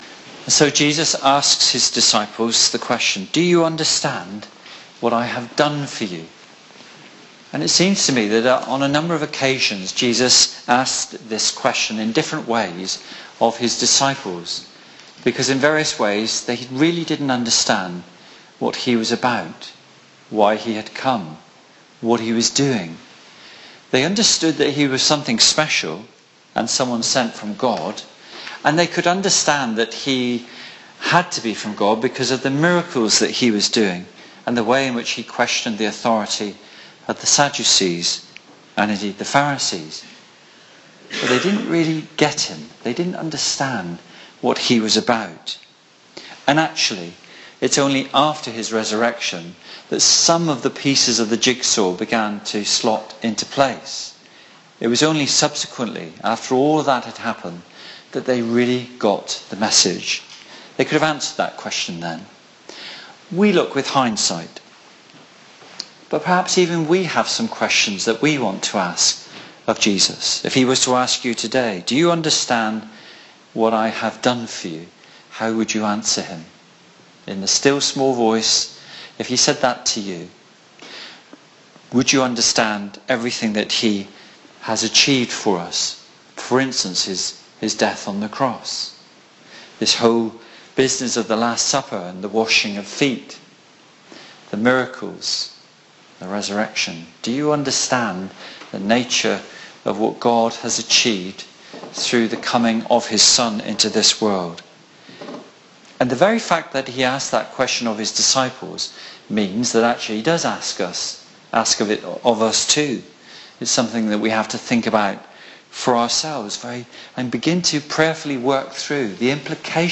Sermon-Maundy-Thursday-2015.mp3